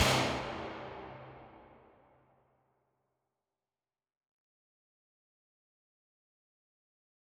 MDMV3 - Hit 1.wav